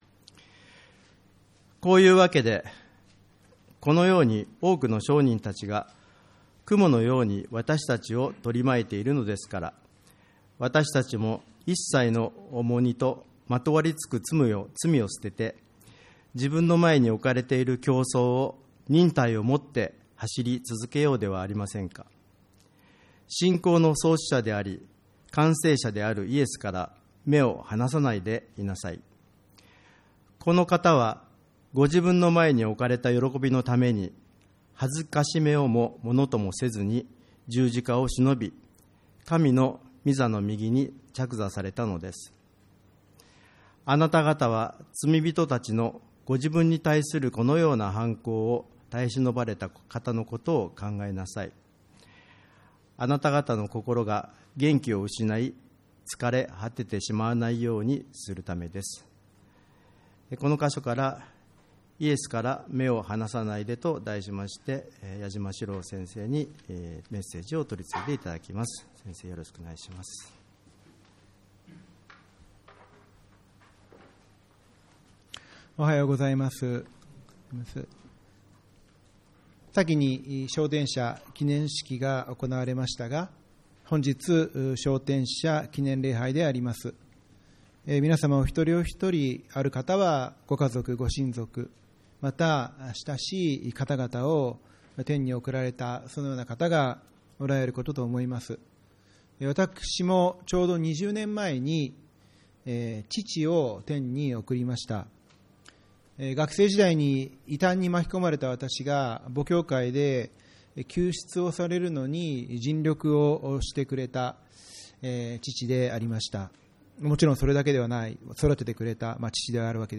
礼拝メッセージ